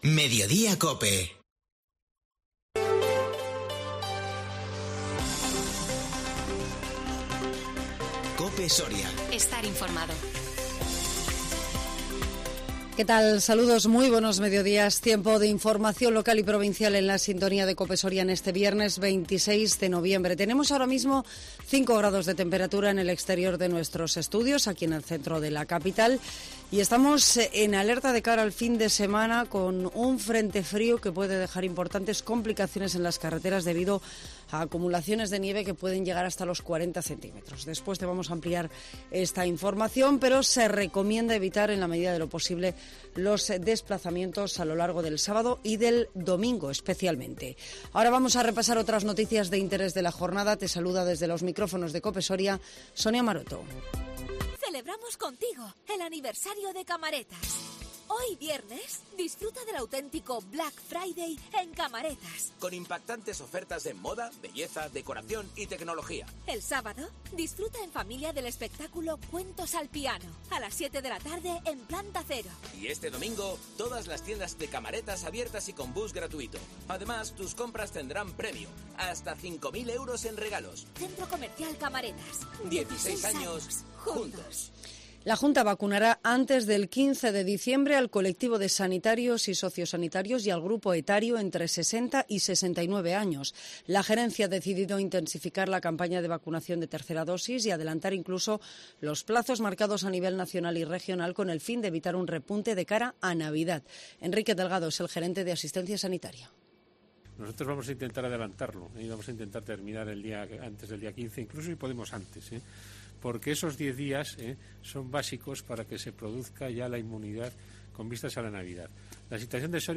INFORMATIVO MEDIODÍA 26 NOVIEMBRE 2021